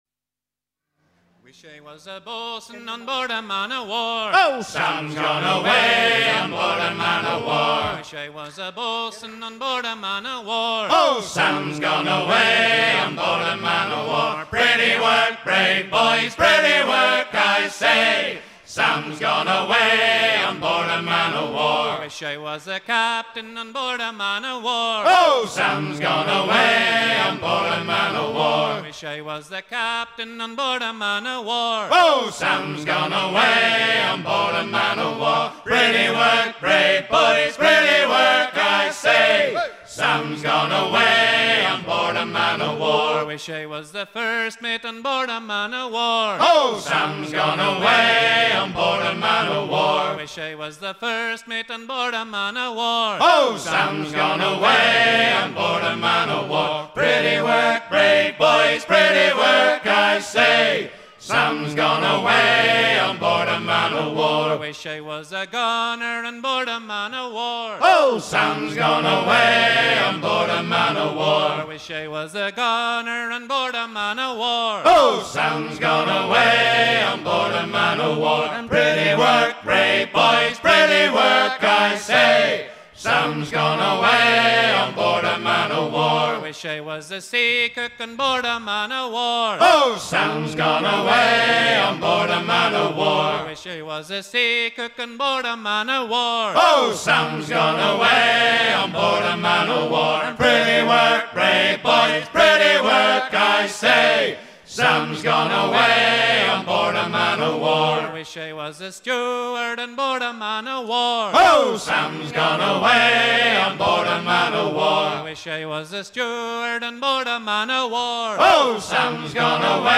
Chant de gaillard d'avant